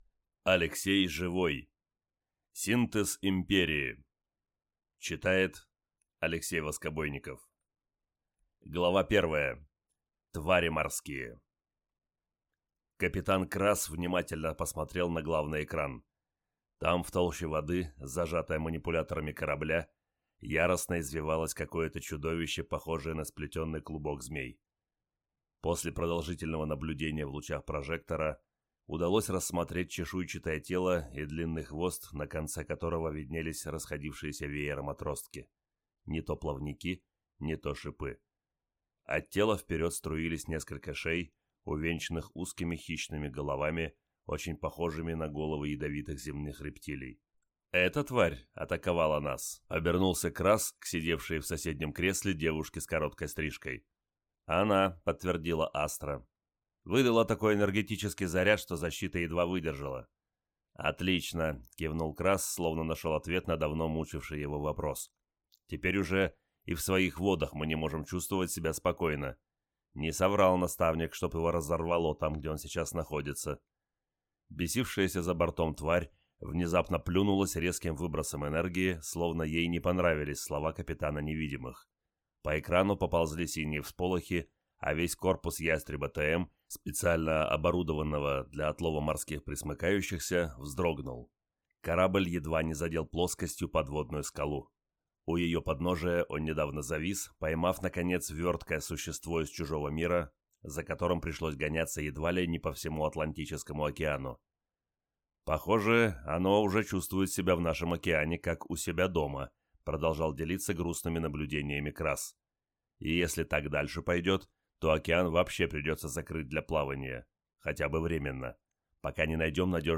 Аудиокнига Синтез империи | Библиотека аудиокниг